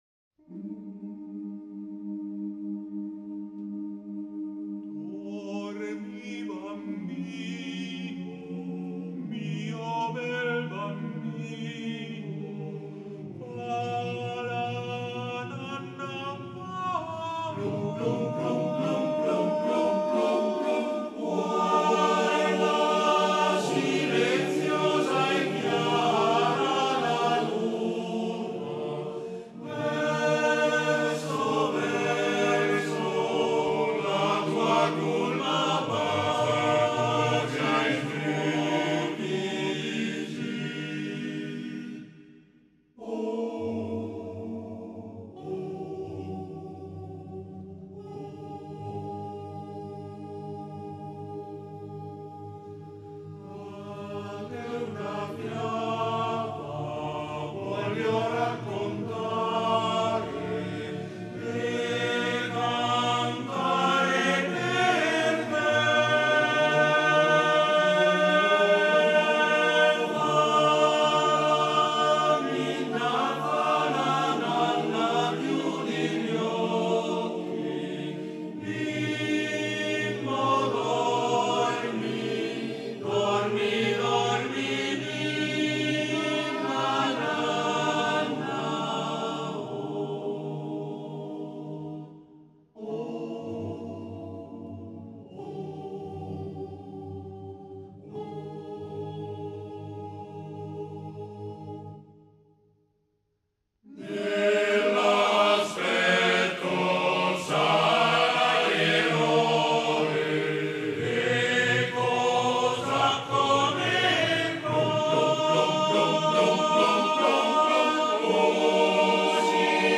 Arrangiatore: Dorigatti, Camillo (armonizzatore)
Esecutore: Coro SOSAT